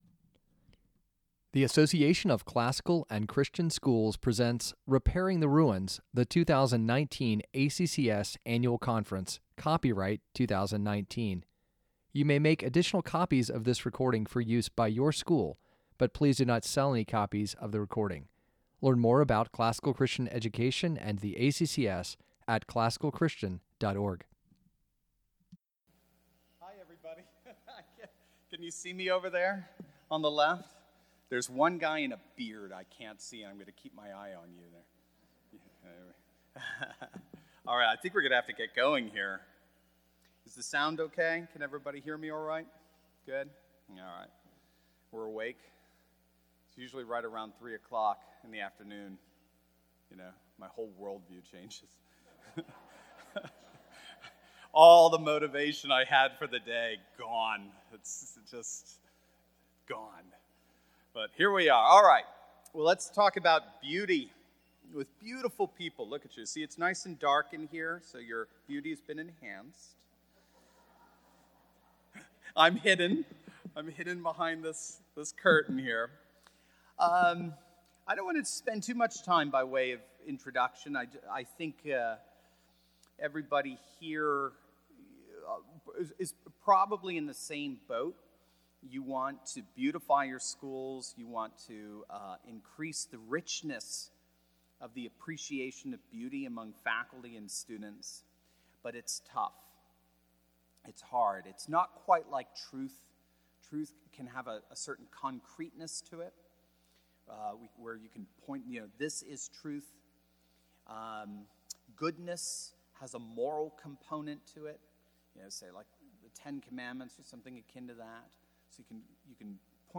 2019 Foundations Talk | 01:03:47 | All Grade Levels, Operations & Facilities, Teacher & Classroom